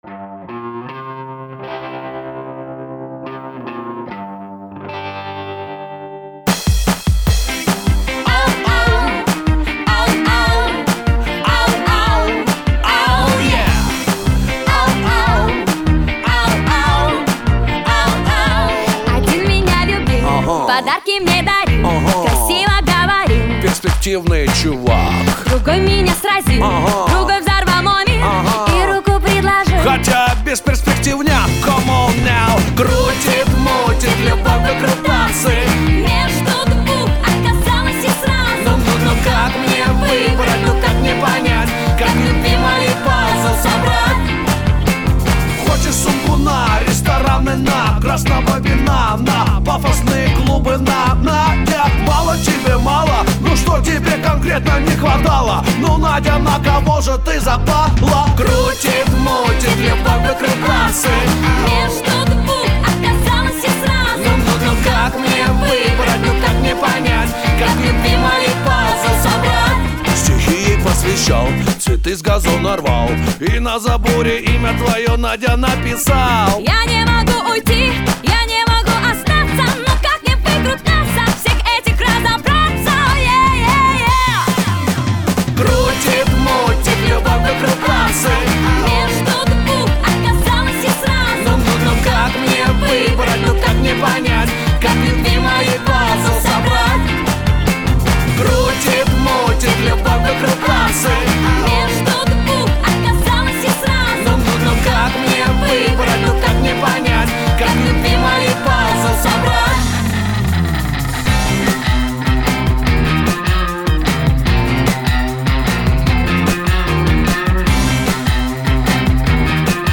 Жанр-русская попса